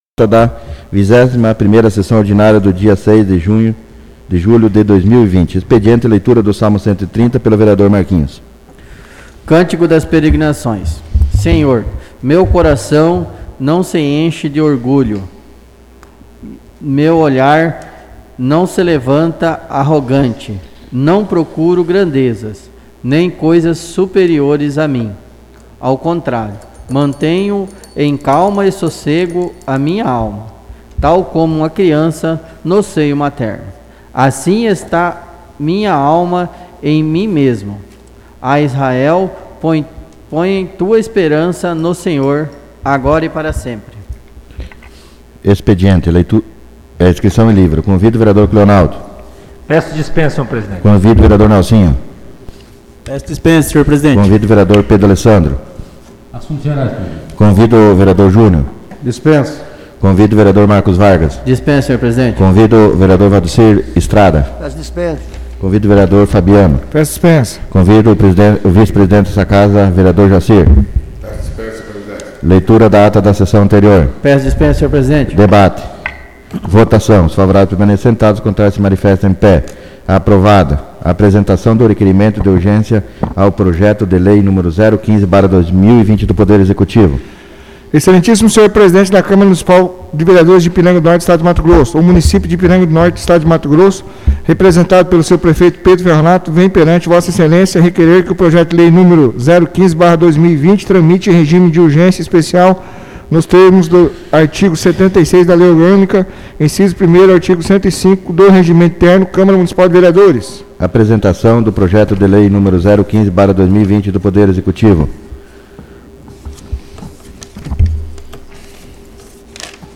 sessão ordinária